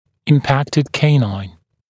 [ɪm’pæktɪd ‘keɪnaɪn] [им’пэктид ‘кейнайн] ретинированный клык